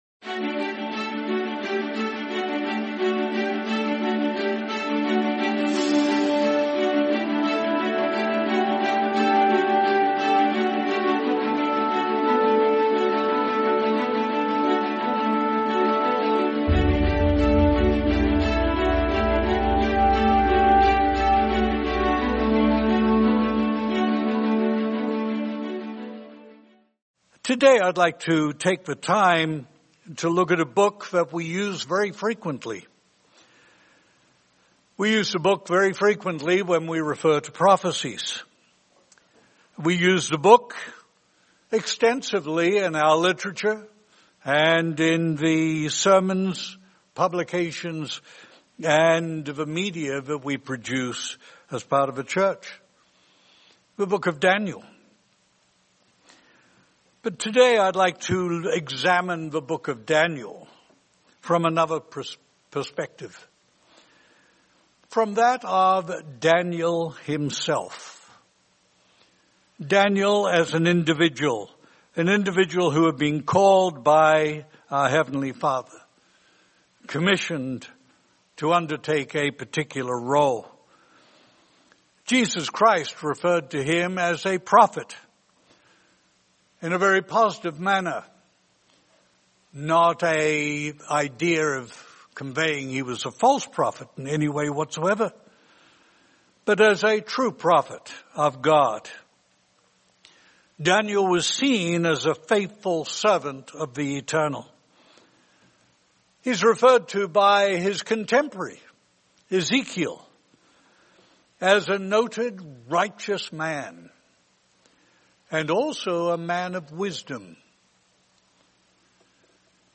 Lessons for Living at the End of the Age | Sermon | LCG Members